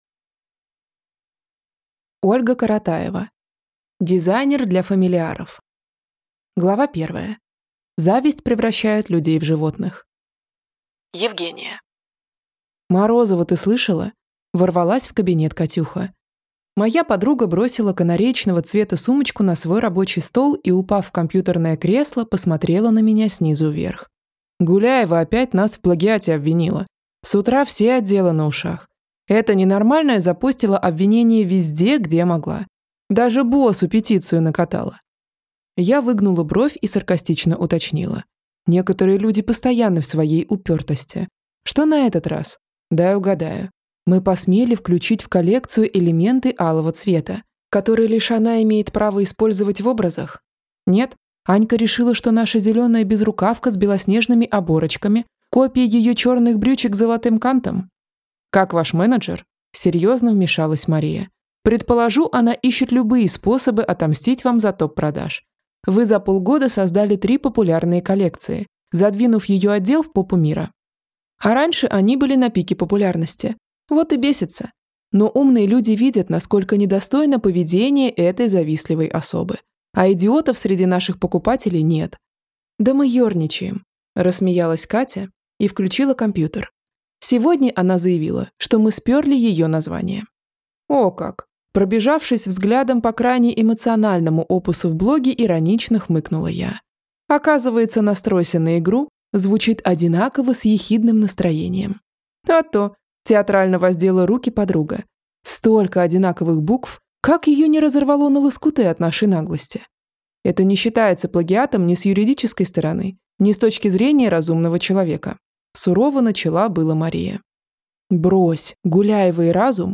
Аудиокнига Дизайнер одежды для фамильяров | Библиотека аудиокниг
Прослушать и бесплатно скачать фрагмент аудиокниги